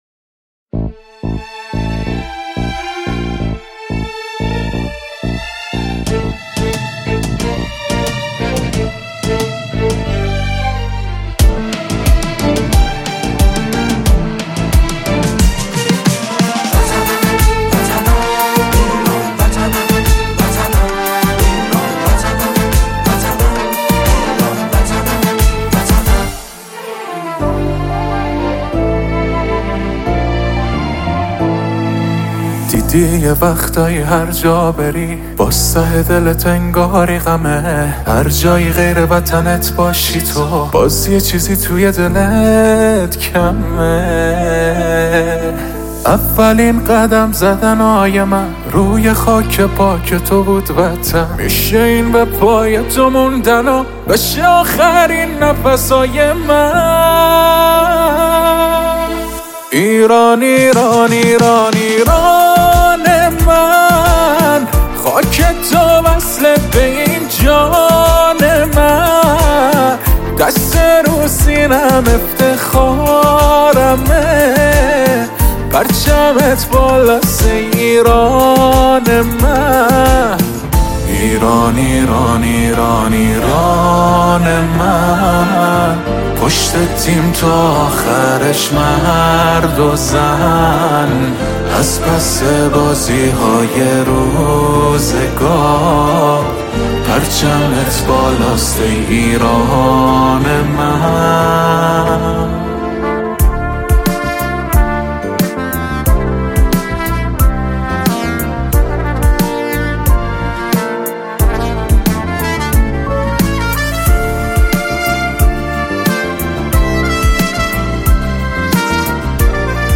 حماسی و ارزشی